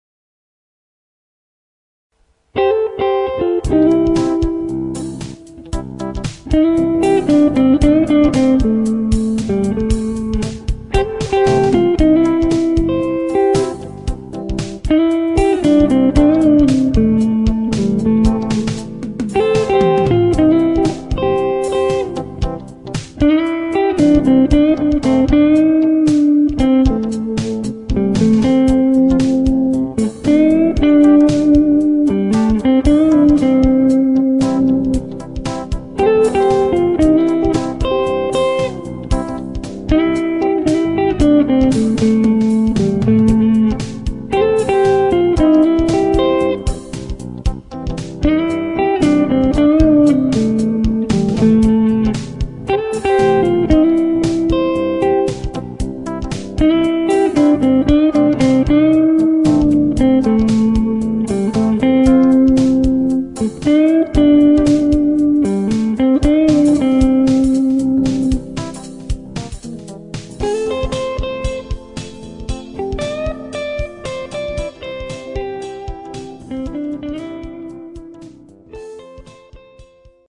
A lounge album with synthesised backings.
Nice guitar tone from my Gibson 335 on this one.